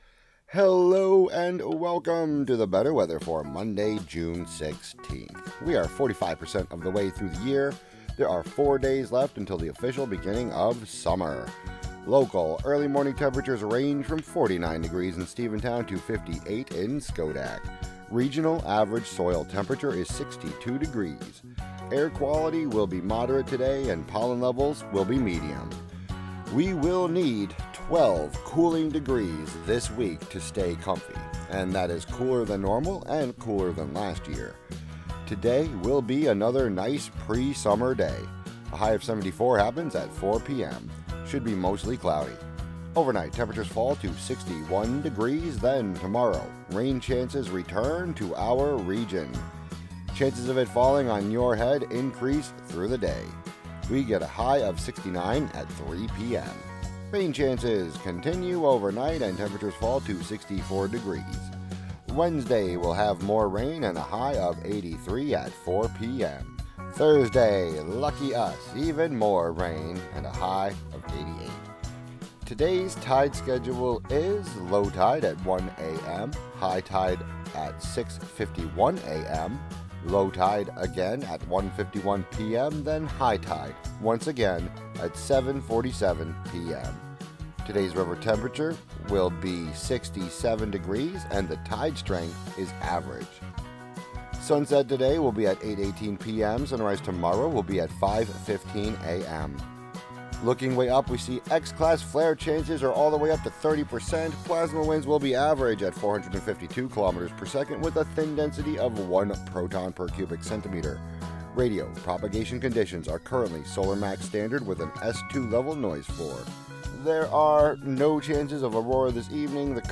and more on WGXC 90.7-FM.